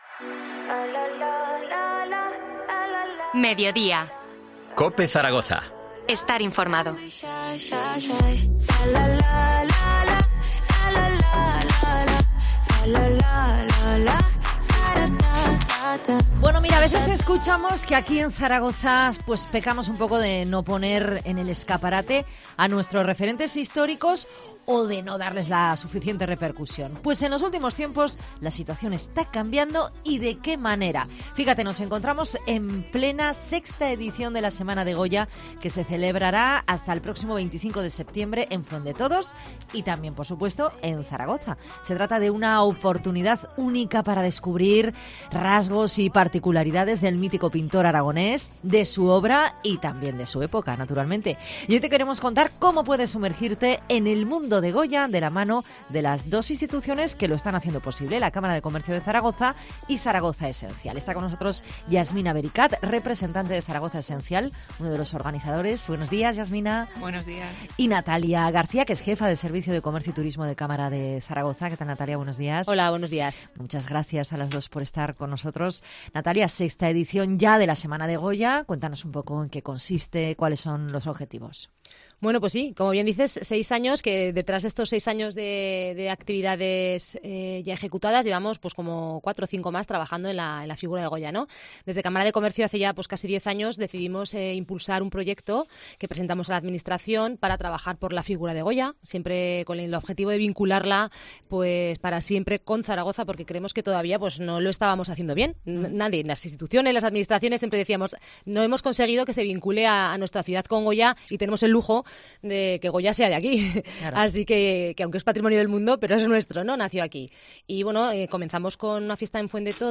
ENTREVISTA SEMANA DE GOYA 21-9-22